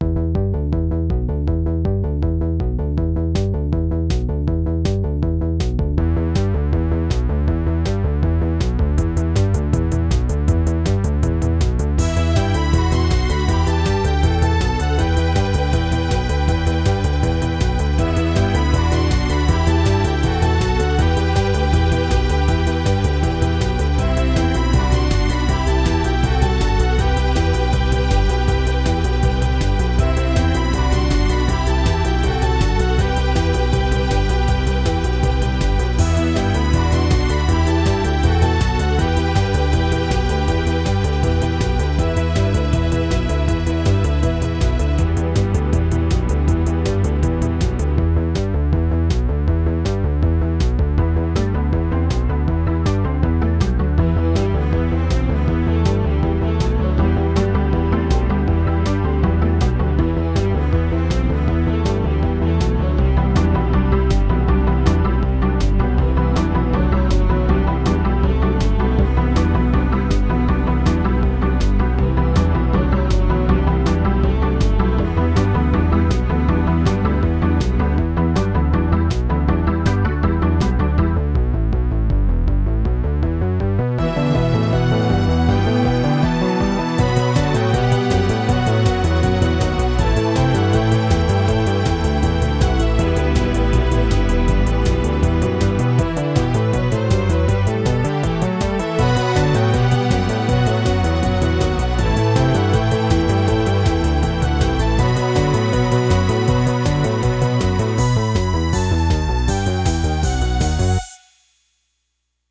This time, the map was rather hectic, so I went with a high BPM, high-energy string melodies, and at the request of the mapper, a bassline inspired by TNT: Evilution's track "Let's Kill At Will."